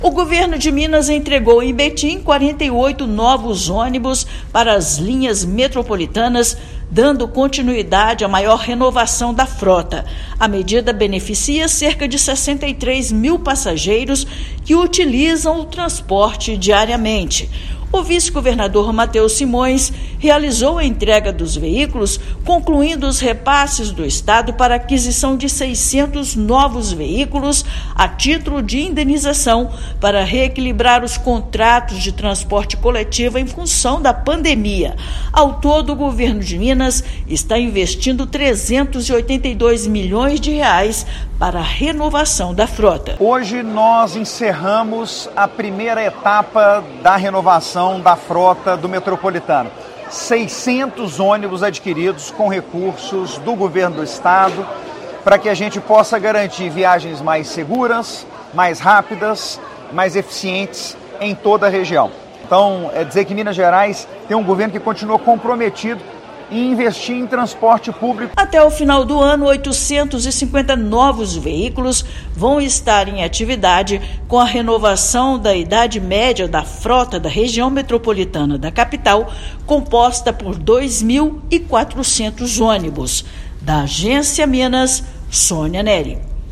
Medida vai beneficiar cerca de 63 mil passageiros da RMBH com veículos mais confortáveis, menos poluentes e que emitem menos ruídos. Ouça matéria de rádio.